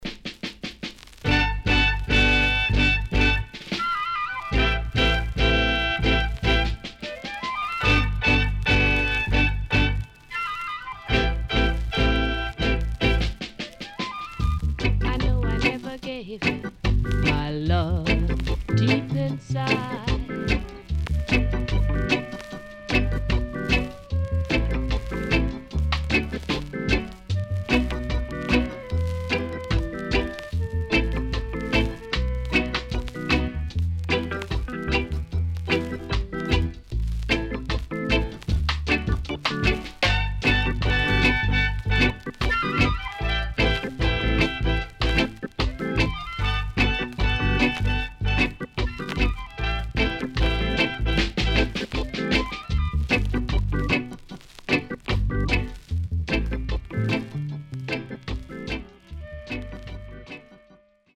Good Rocksteady & LOVE OF A WOMAN Inst
SIDE A:序盤少しノイズ入りますが良好です。